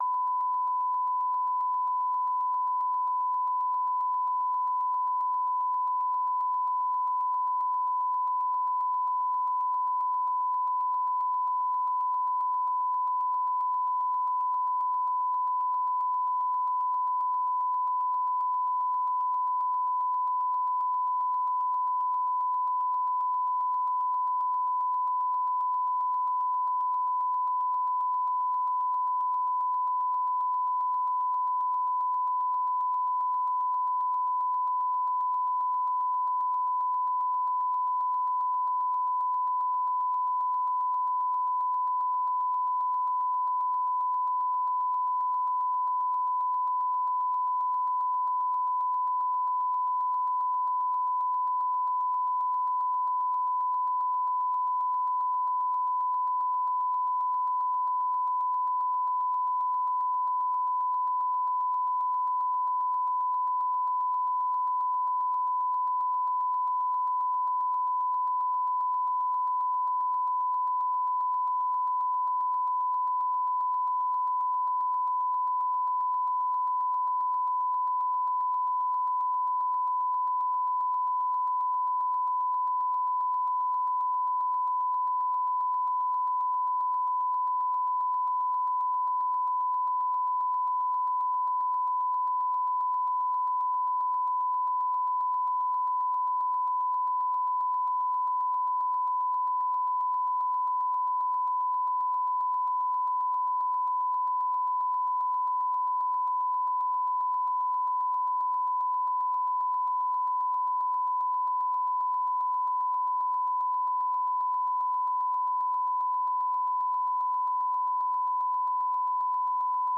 Una transmisión simultanea del noticiero de televisión “El mundo al día” en radio.